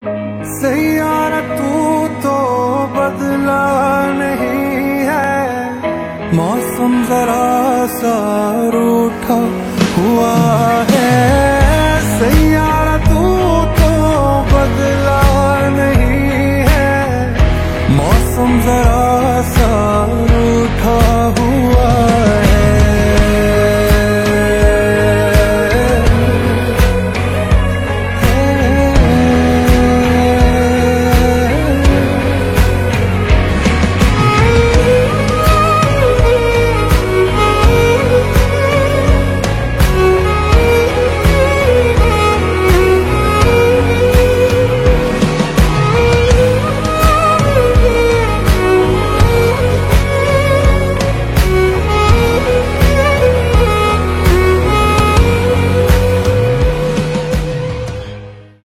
поп , индийские